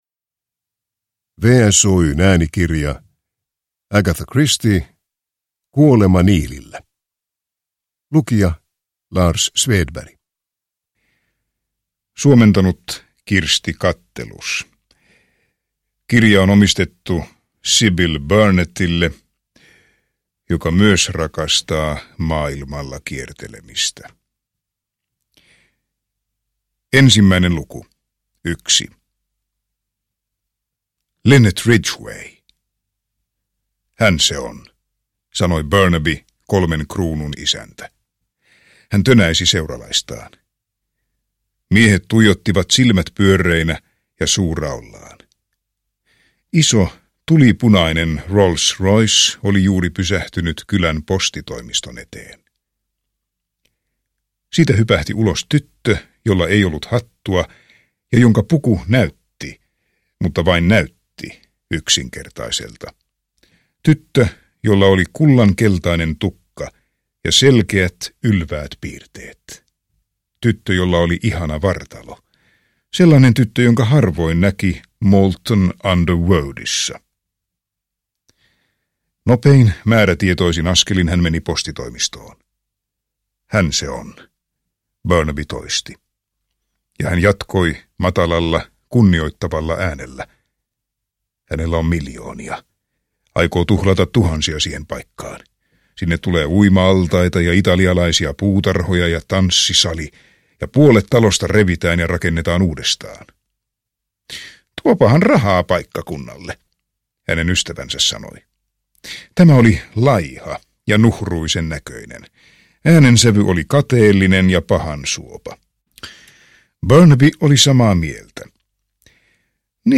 Kuolema Niilillä – Ljudbok – Laddas ner